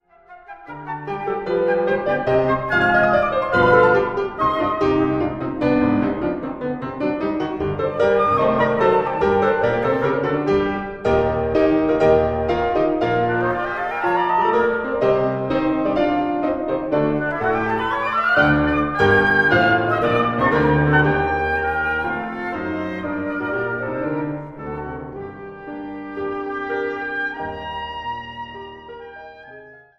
Kammermusik für Bläser und Klavier